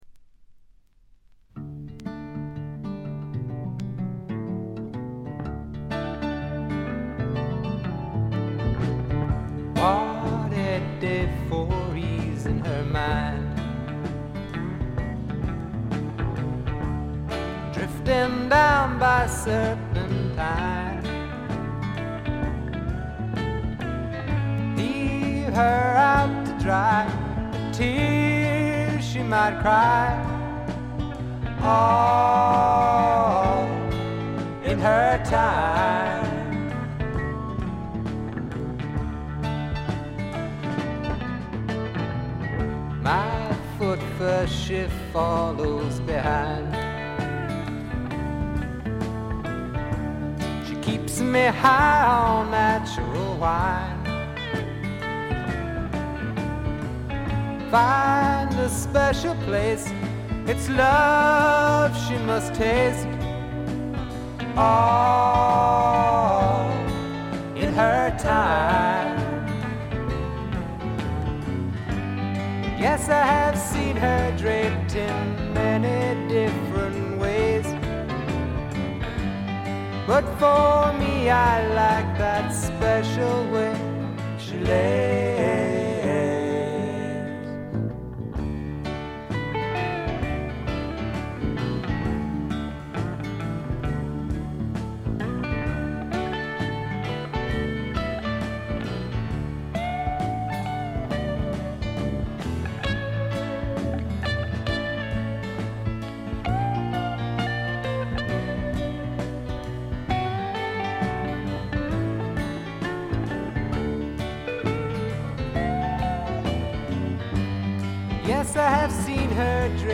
部分試聴ですが、軽微なチリプチ程度。
試聴曲は現品からの取り込み音源です。